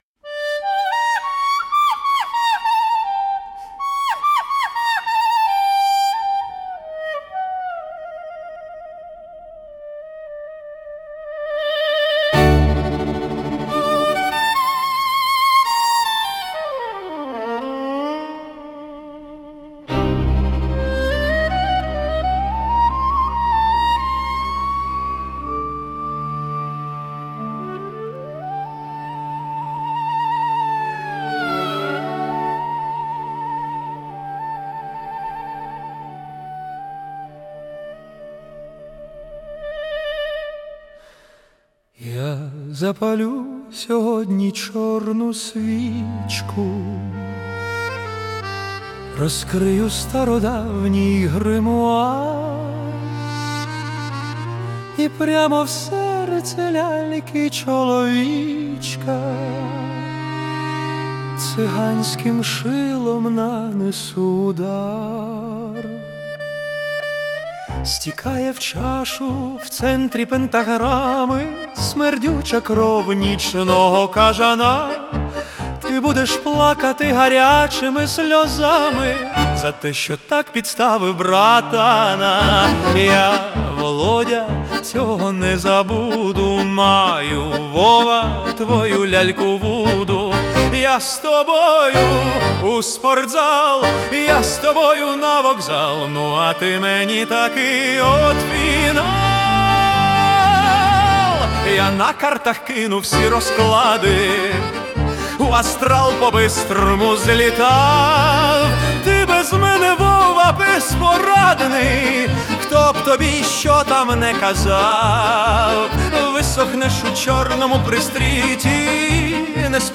ТИП: Пісня
СТИЛЬОВІ ЖАНРИ: Гумористичний